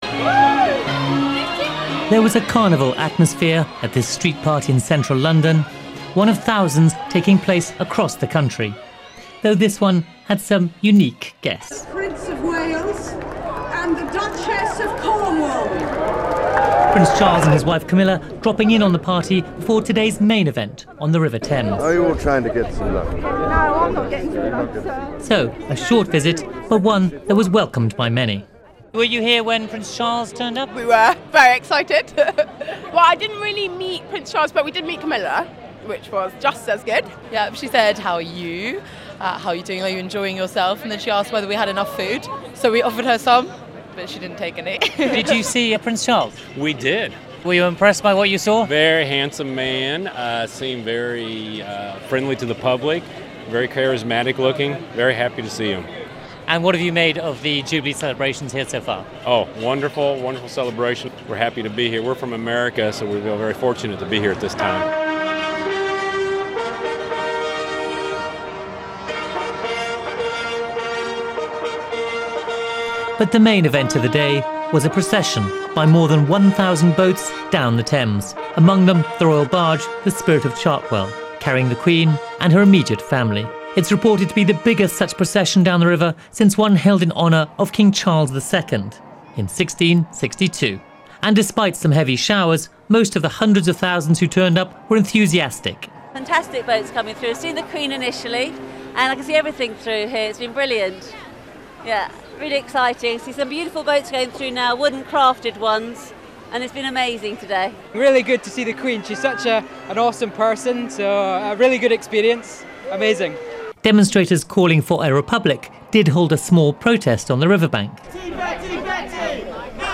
A Celebration of Royalty, with rain. On Sunday the third of June 2012, London celebrated the Queen's Diamond Jubilee.